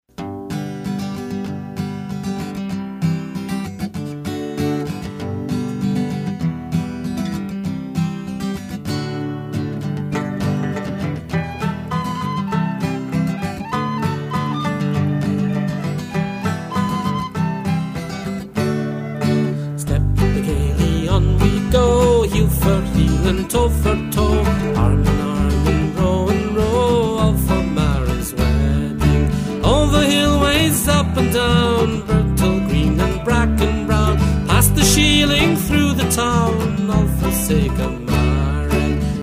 San Francisco Celtic Singer 1
Today, this artist performs solo Celtic/Popular music, and with his band.
San-Francisco-Irish-Singer-4-Mairis_wedding.mp3